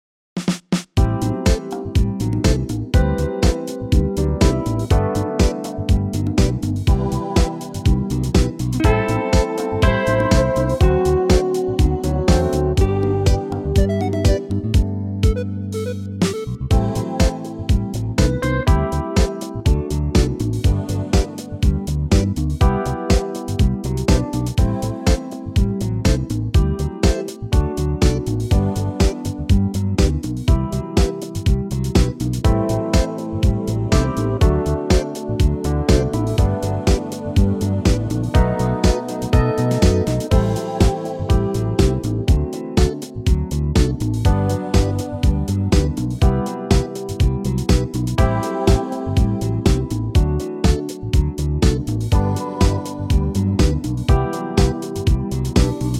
key - G - vocal range - C to E
-Unique Backing Track Downloads